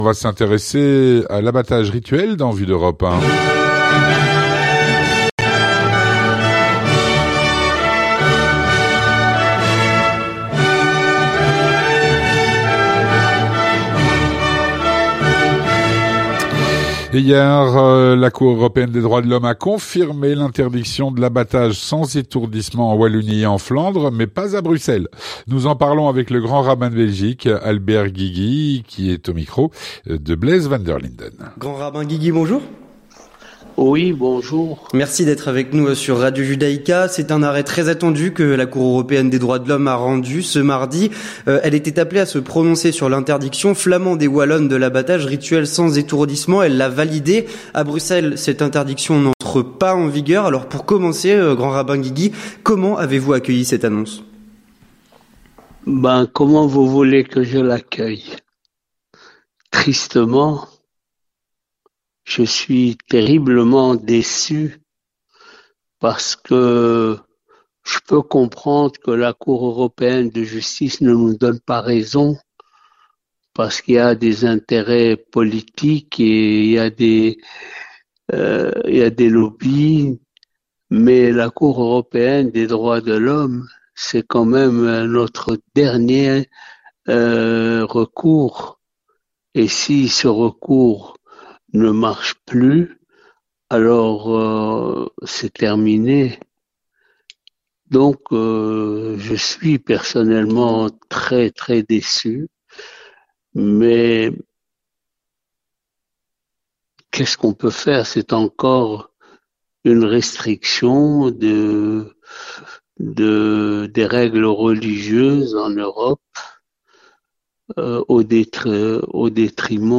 Avec Albert Guigui, Grand Rabbin de Bruxelles